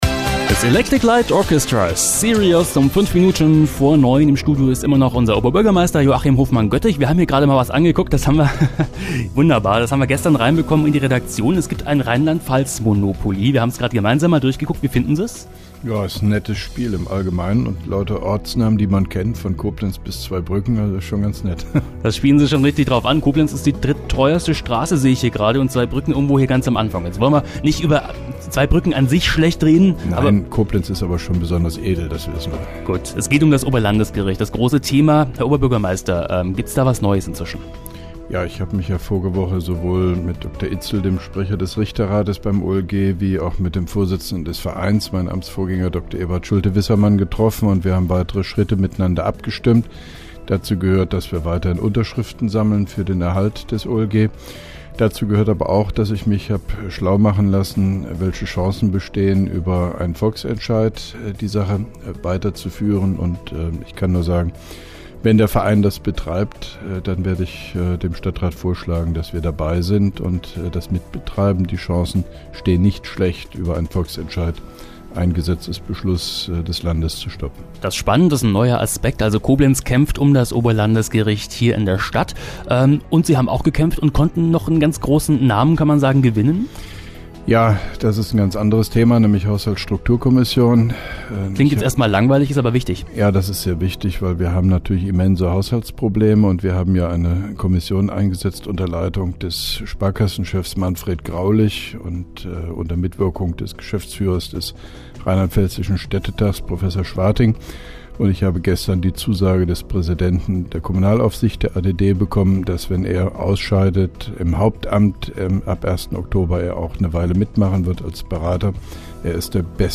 (3) Koblenzer Radio-Bürgersprechstunde mit OB Hofmann-Göttig 26.07.2011
Interviews/Gespräche